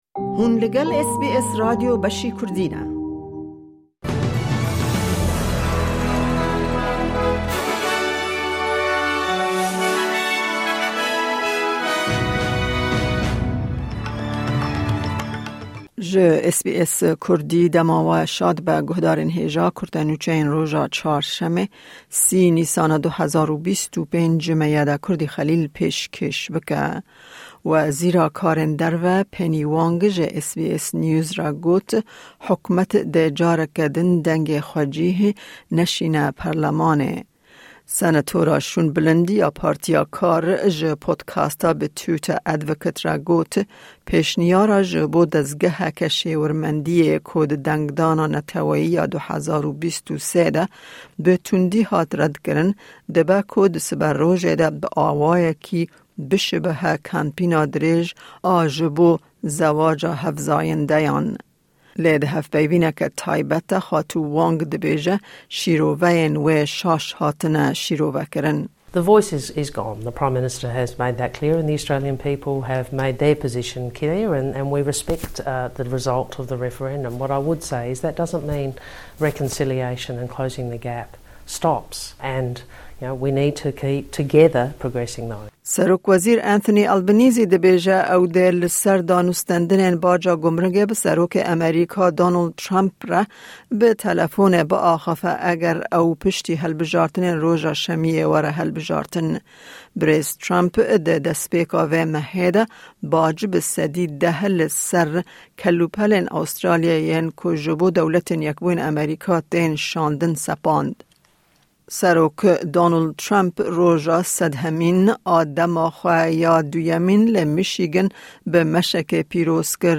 Kurte Nûçeyên roja Çarşemê, 30î Nîsana 2025